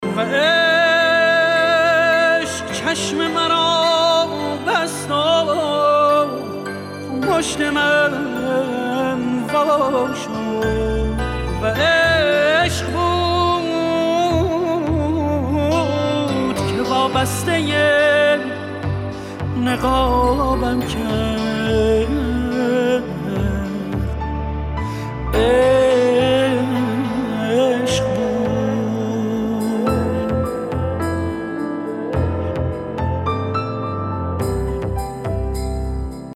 رینگتون با کلام ملایم